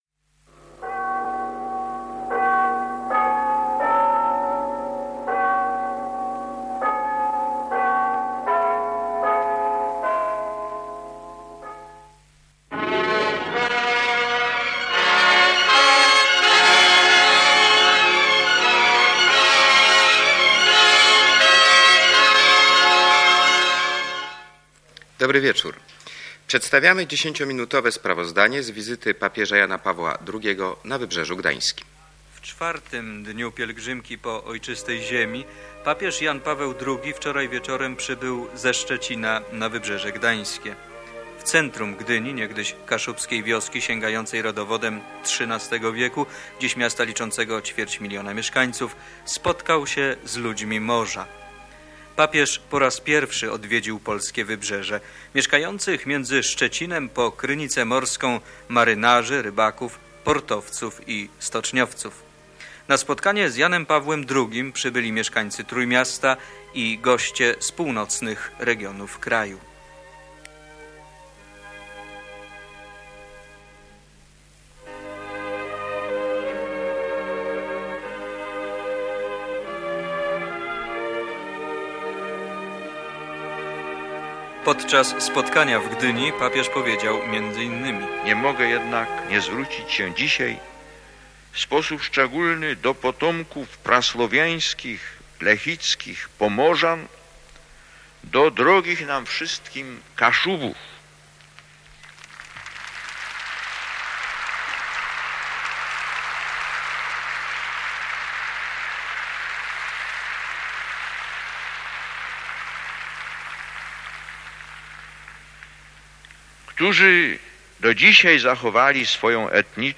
Wizyta Jana Pawła II na Wybrzeżu Gdańskim: sprawozdanie TV Gdańsk [dokument dźwiękowy] - Pomorska Biblioteka Cyfrowa